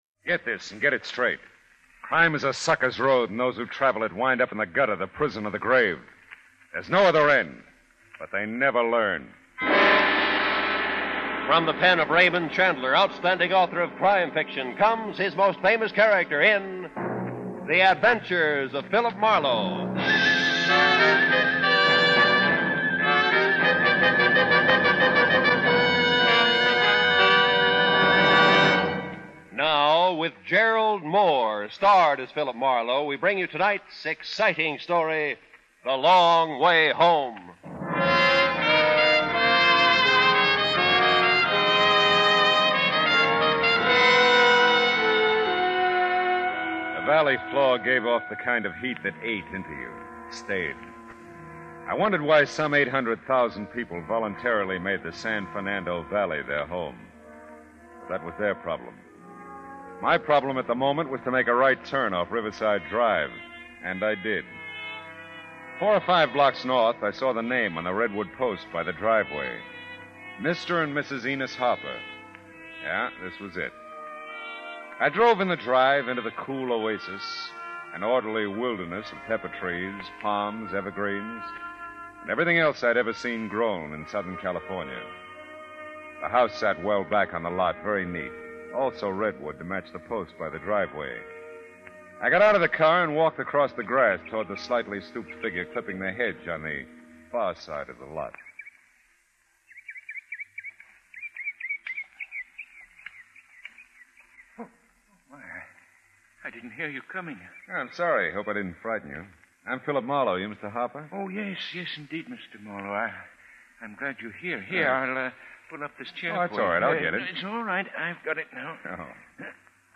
The program first aired 17 June 1947 on NBC radio under the title The New Adventures of Philip Marlowe, with Van Heflin playing Marlowe.
In 1948, the series moved to CBS, where it was called The Adventure of Philip Marlowe, with Gerald Mohr playing Marlowe.